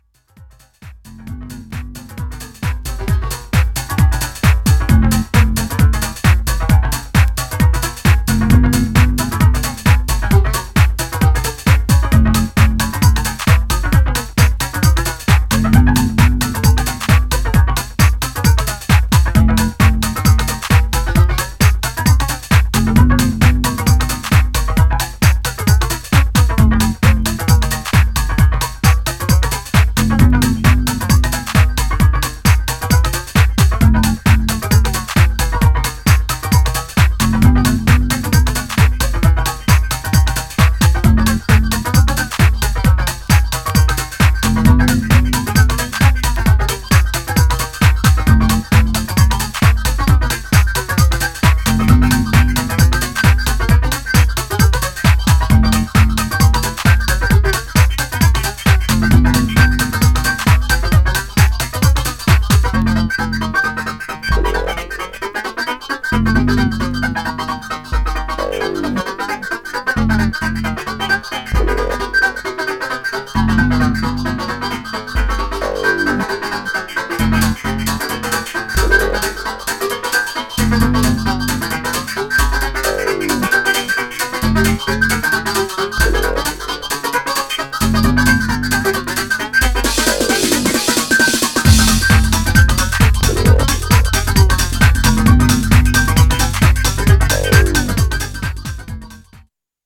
Styl: Techno, Hardtek/Hardcore